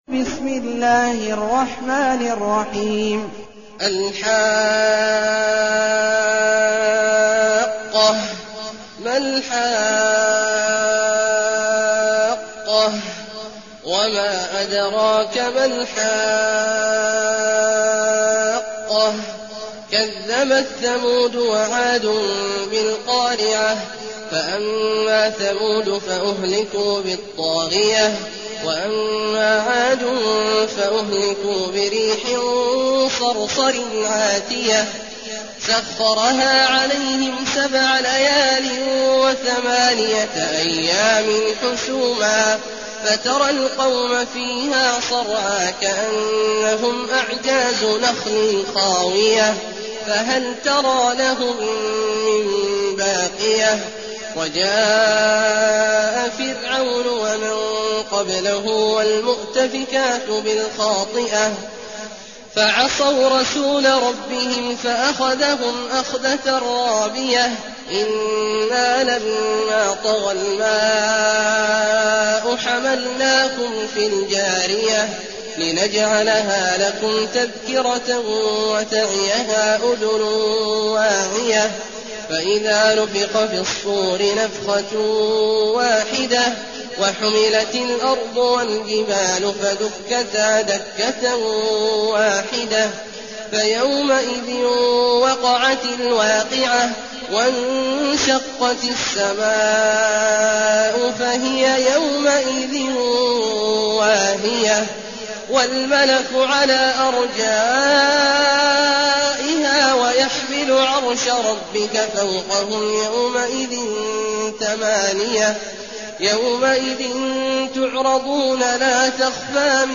المكان: المسجد الحرام الشيخ: عبد الله عواد الجهني عبد الله عواد الجهني الحاقة